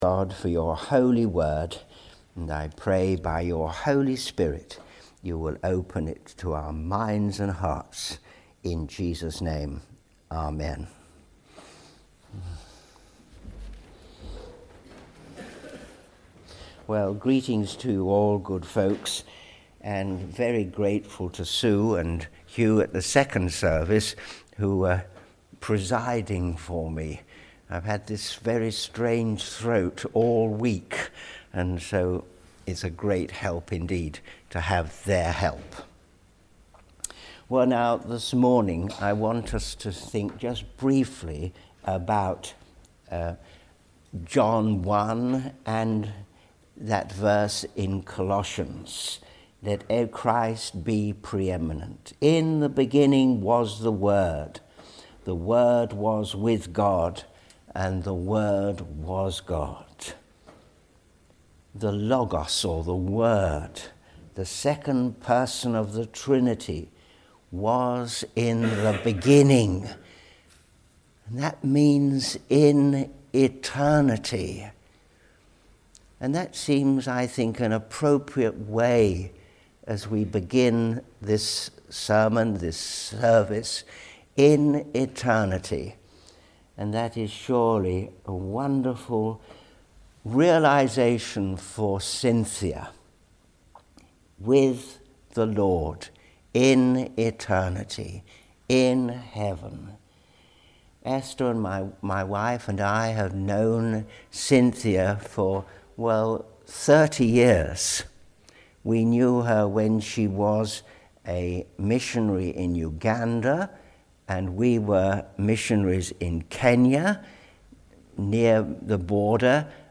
Continuing our sermon series “Some Marks of Growing” Commitment – Thanksgiving Sunday – 2 Corinthians 8:1-15